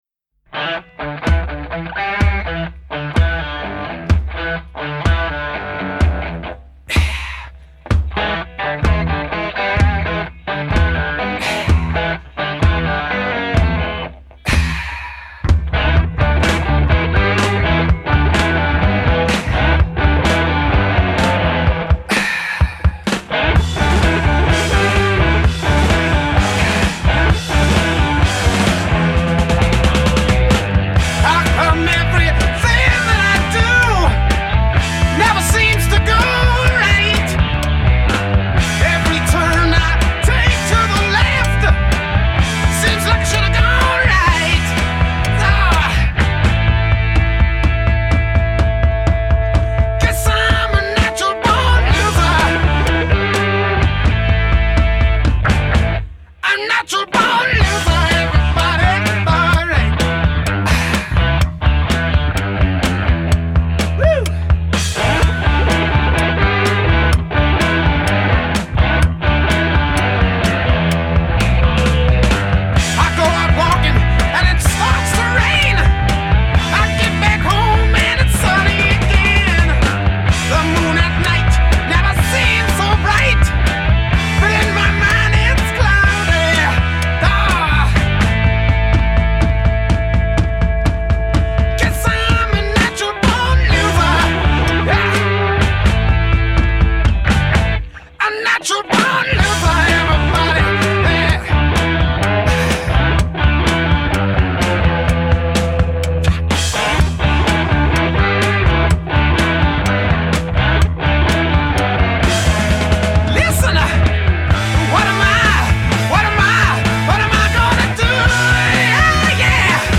Genre: Rock, Glam Rock